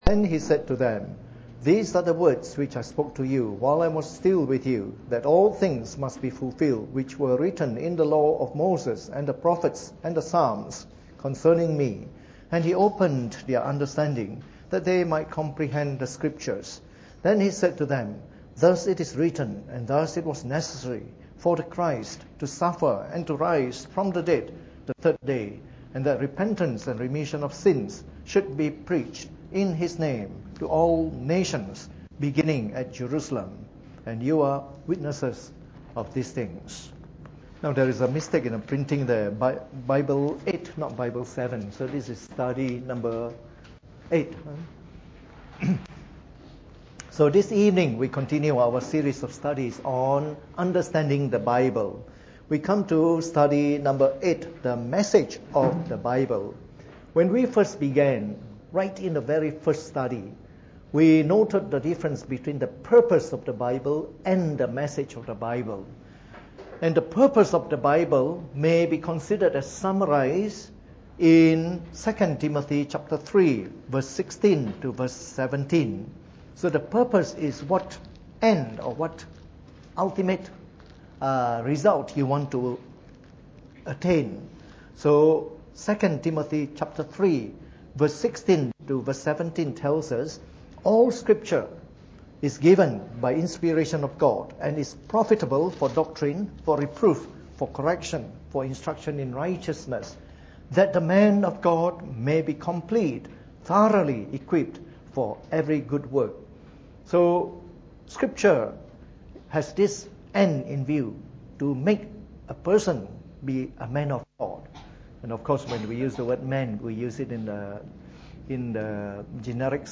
Preached on the 13th of May 2015 during the Bible Study, from our series of talks on Knowing the Bible.